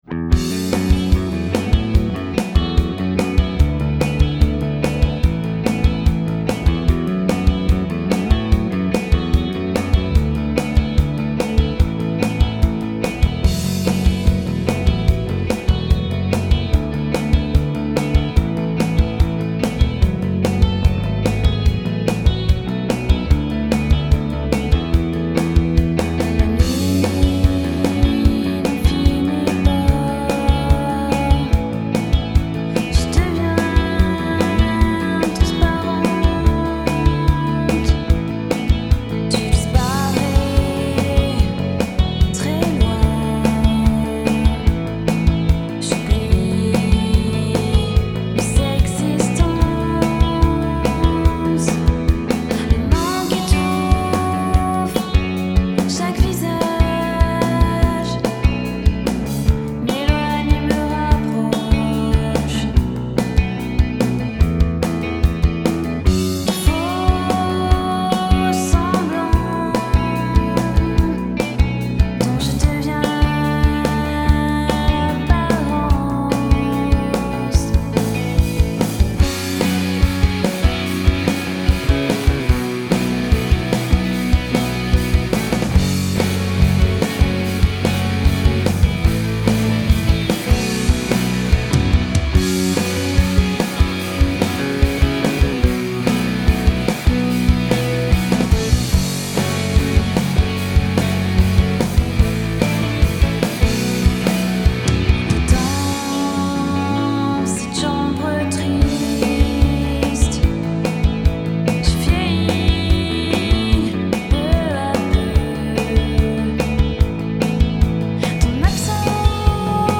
vokal yang sama-sama berkarakter innocent